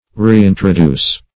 Reintroduce \Re*in`tro*duce"\ (r?*?n`tr?*d?s"), v. t.